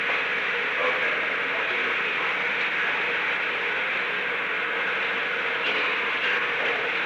On January 29, 1972, President Richard M. Nixon and unknown person(s) met in the Oval Office of the White House at an unknown time between 12:10 pm and 12:12 pm. The Oval Office taping system captured this recording, which is known as Conversation 660-012 of the White House Tapes. Nixon Library Finding Aid: Conversation No. 660-12 Date: January 29, 1972 Time: Unknown between 12:10 pm and 12:12 pm Location: Oval Office The President met with an unknown person.